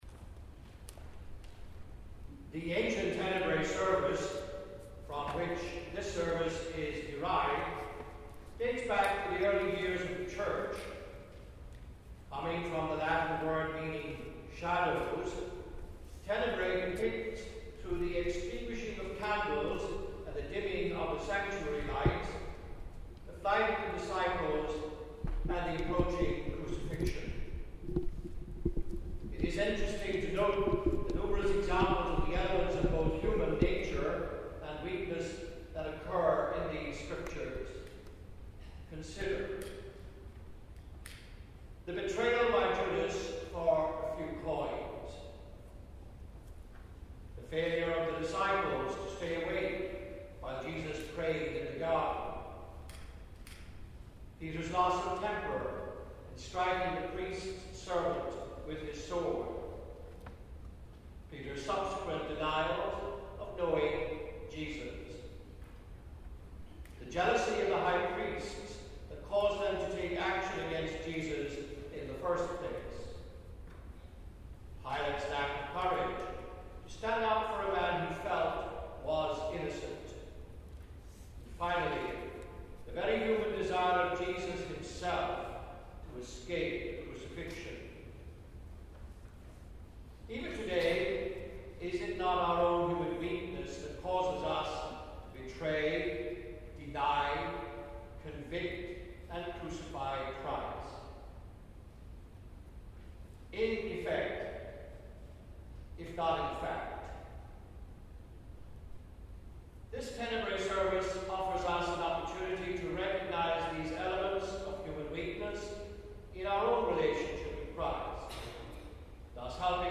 The St. William choir presented a Tenebrae Service on Palm Sunday 2015.
Reading "The Betrayal"